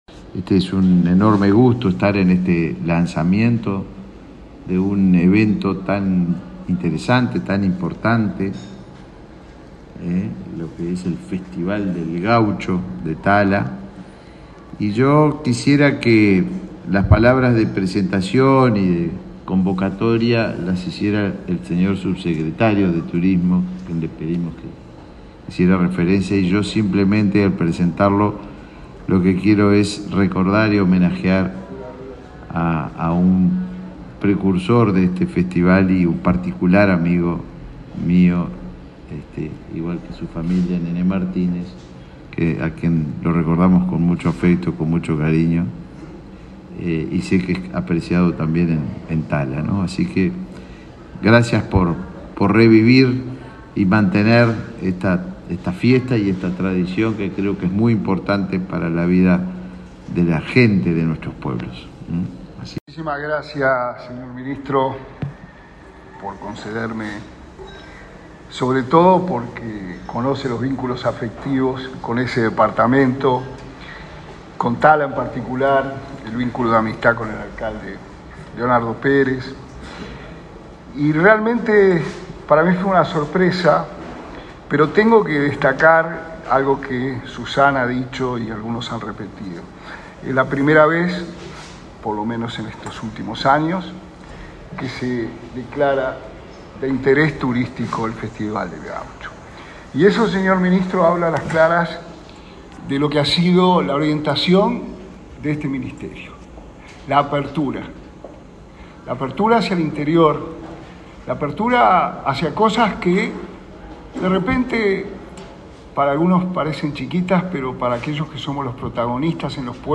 Palabras de autoridades del Ministerio de Turismo